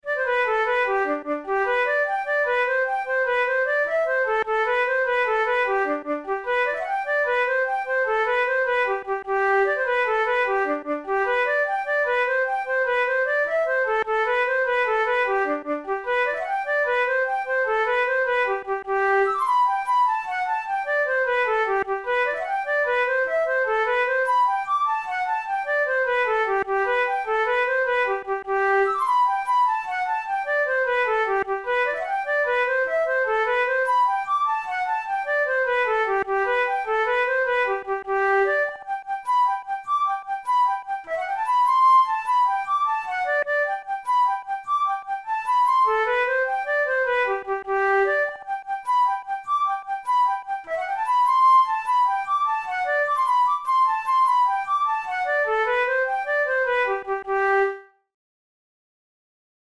InstrumentationFlute solo
KeyG major
Time signature6/8
Tempo100 BPM
Jigs, Traditional/Folk
Traditional Irish jig